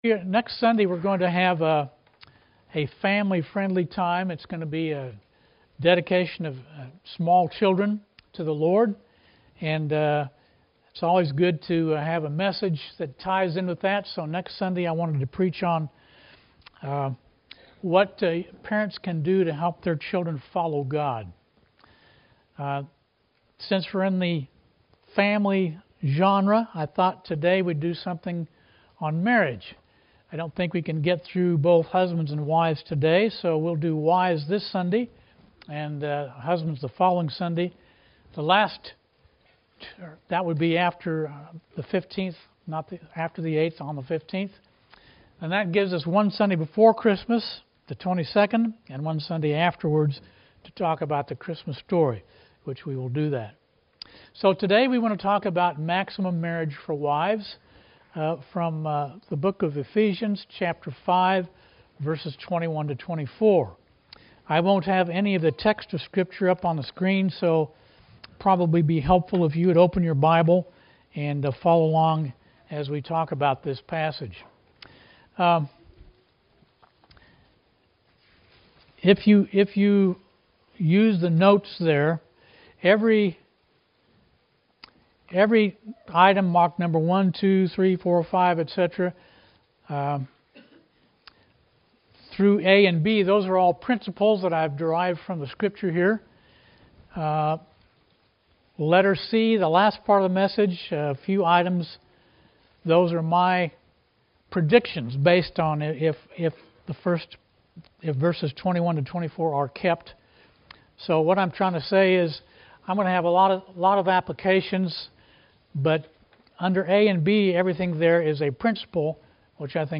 Expository Sermons on Ephesians.